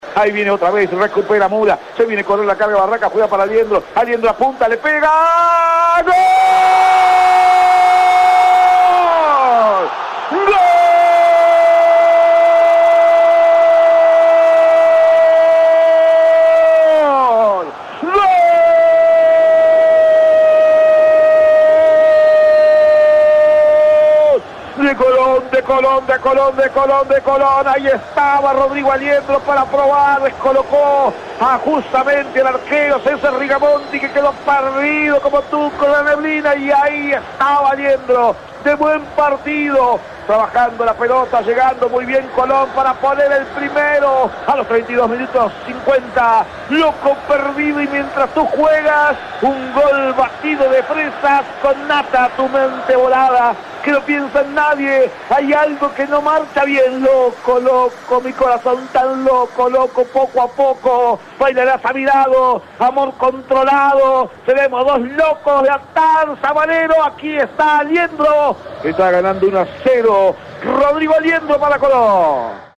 01-GOL-COLON-CENTRAL-CORDOBA.mp3